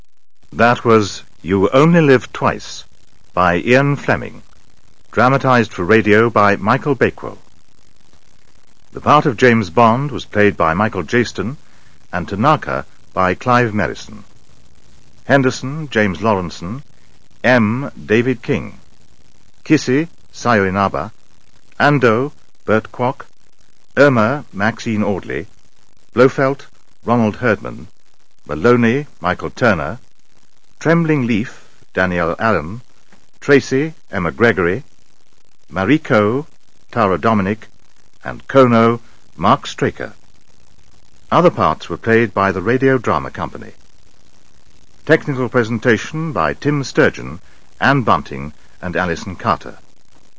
In December of 1990, BBC Radio 4 produced a radio play of You Only Live Twice starring Michael Jayston as James Bond.